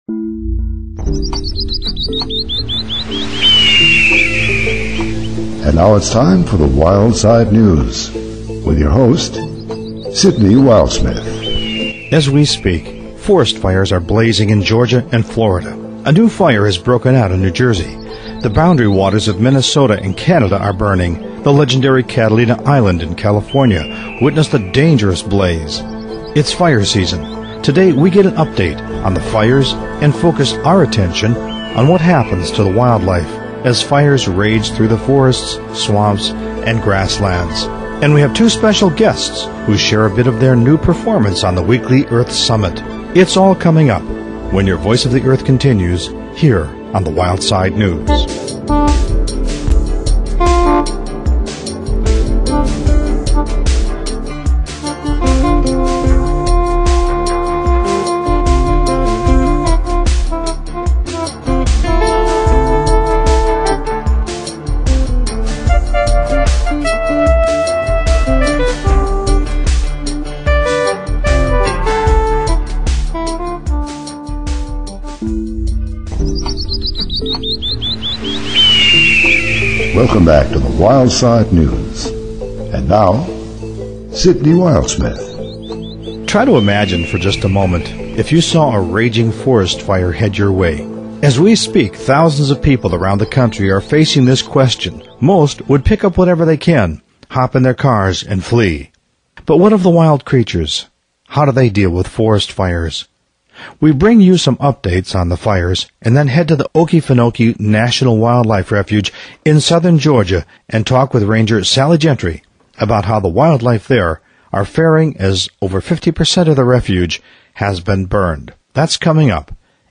The Weekly Earth Summit opens each show. In 15 minutes, you will receive your own briefing on the state of the ark as interpreted by some of the pre-eminent scholars, scientists, authors, activists and spokespeople.